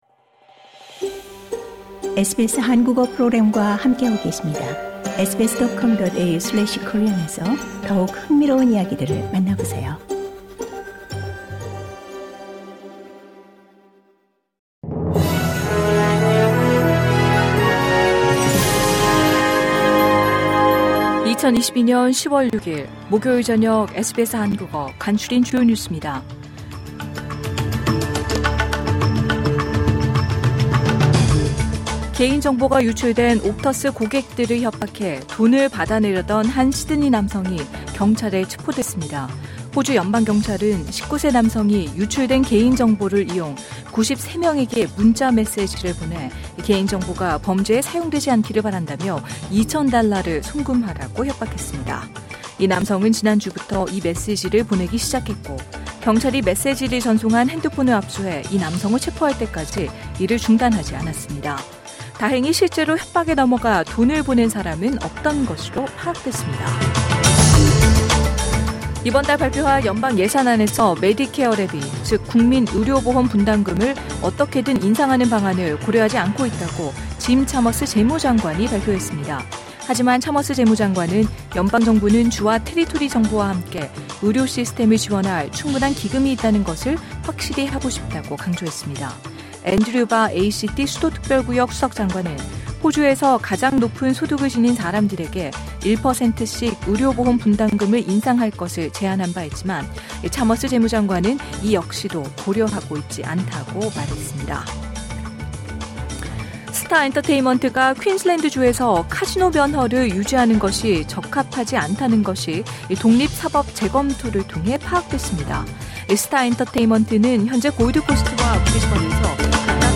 2022년 10월 6일 목요일 저녁 SBS 한국어 간추린 주요 뉴스입니다.